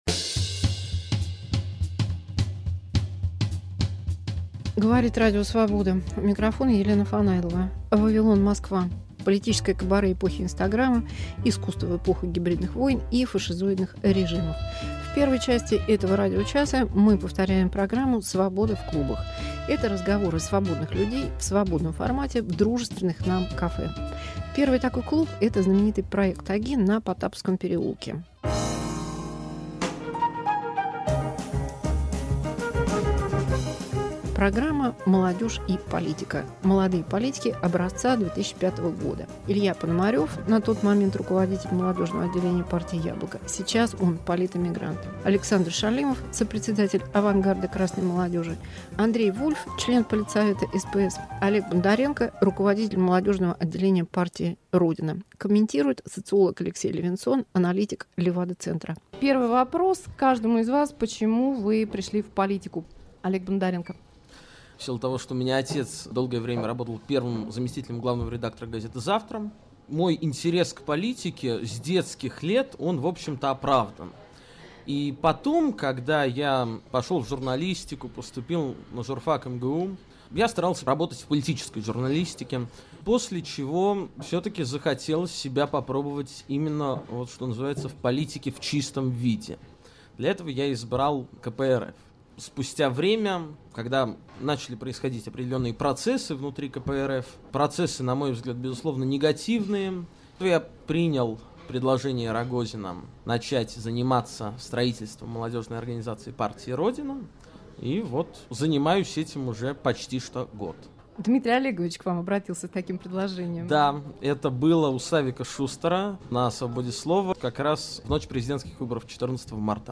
Разговор с молодыми политическими лидерами и активистами, архив "Свободы в клубах" 2005 года